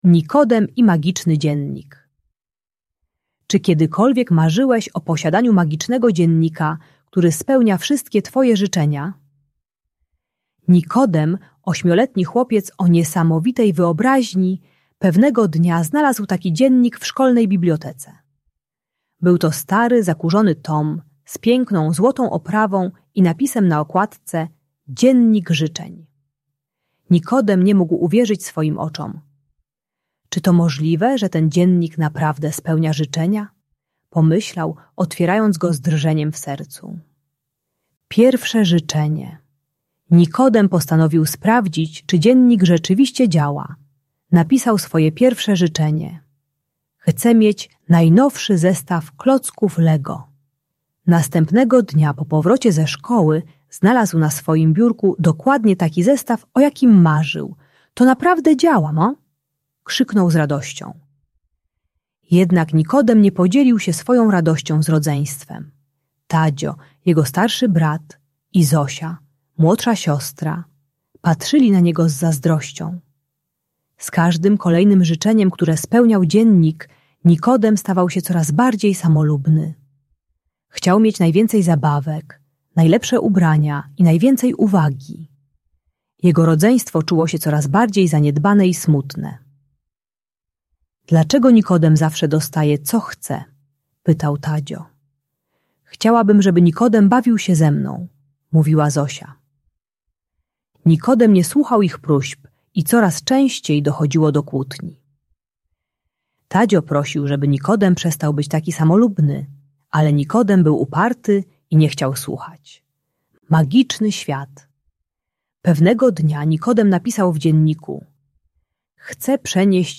Magiczna historia o Nikodemie i dzienniku życzeń - Rodzeństwo | Audiobajka